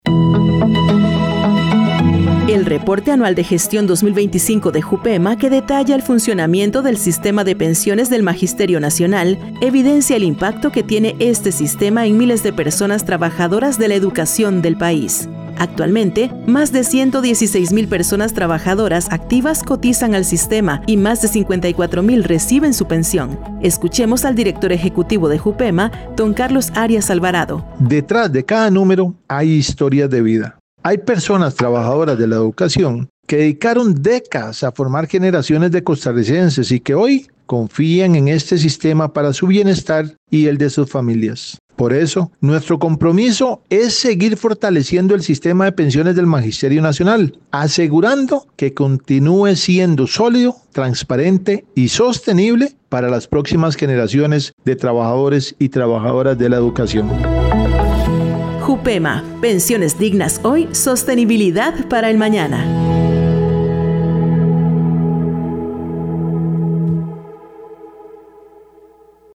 Cápsulas